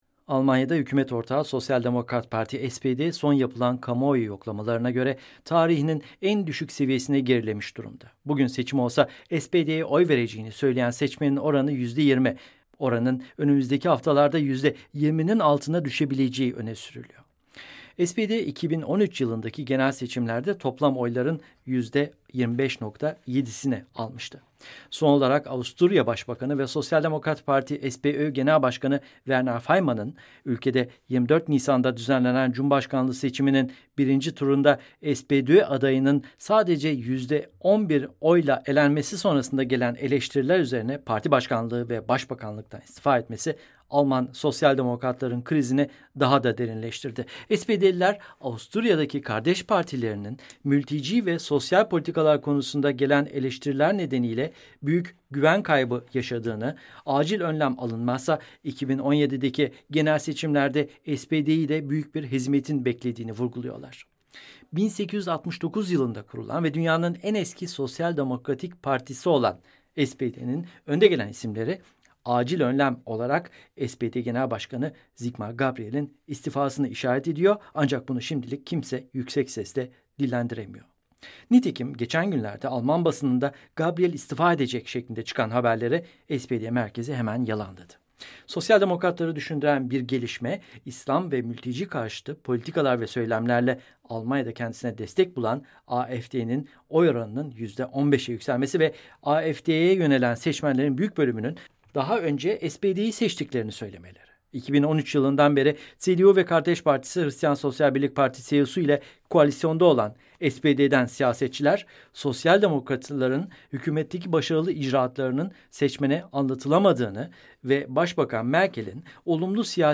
VOA Türkçe - Haberler